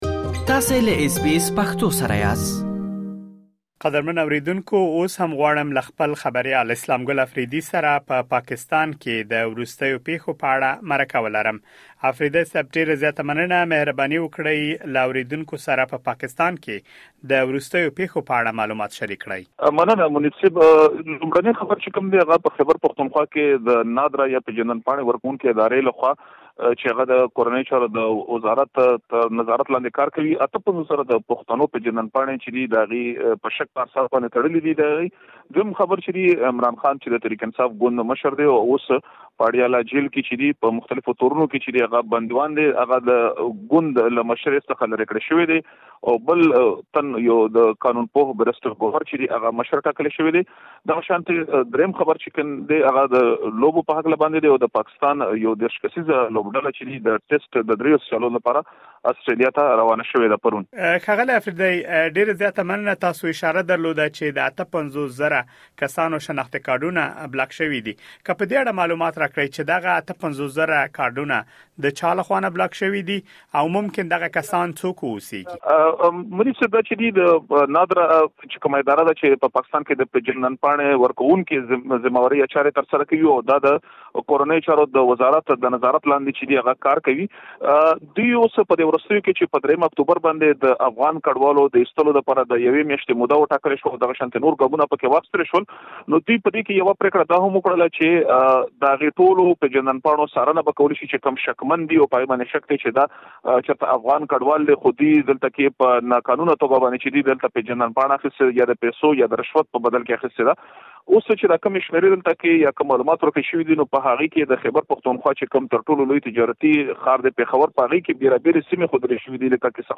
لا ډېر معلومات په مرکې کې اورېدلی شئ.